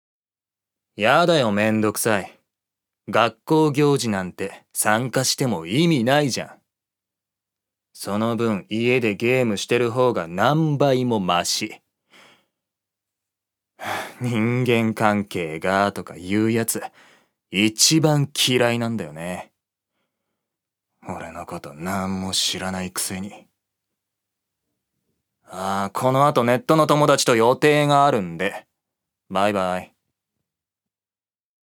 所属：男性タレント
セリフ２